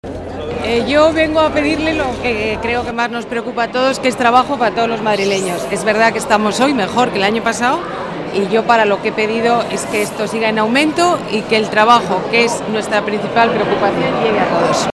Nueva ventana:Ana Botella, alcaldesa, pide trabajo para los madrileños a la Paloma